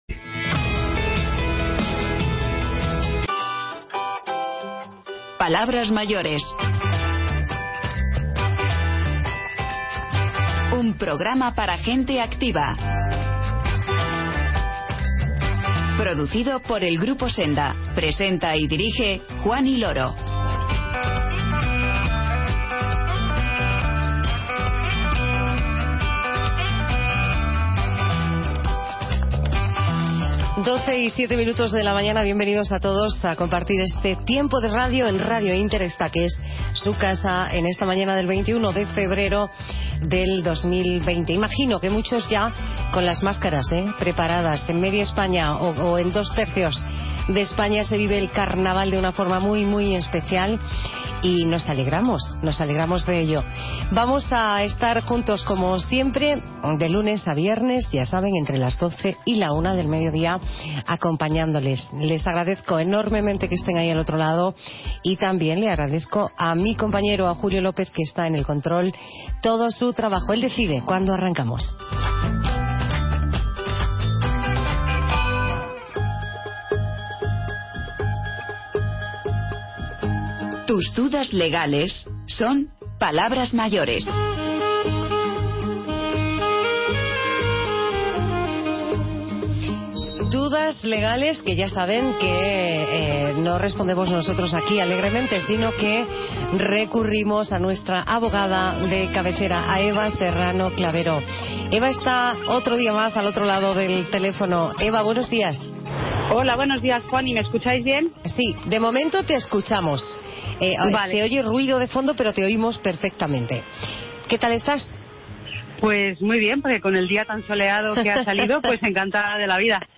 Charlamos con el artista Alberto Corazón, un maestro del lenguaje gráfico que, a sus 88 años, sigue mostrando una ilusión enorme por su trabajo y demostrando que la creatividad no entiende de tiempos.